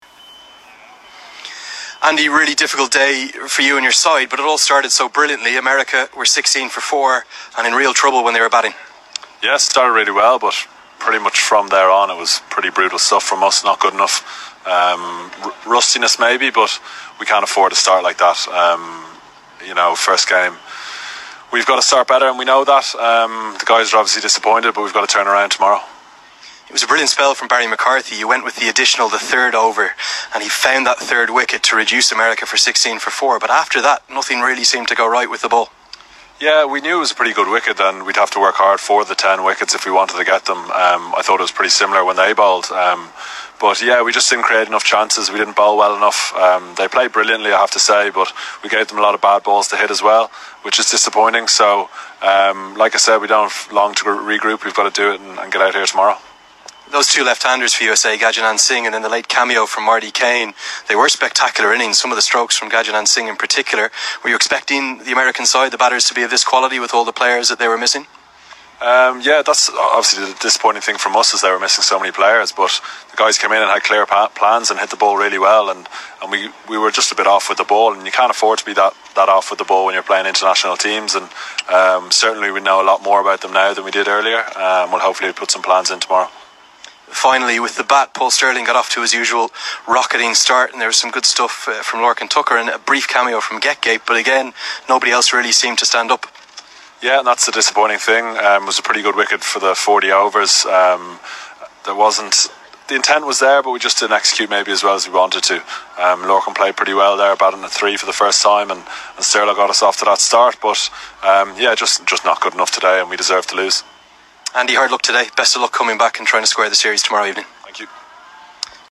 Ireland's Captain Andrew Balbirnie post match interview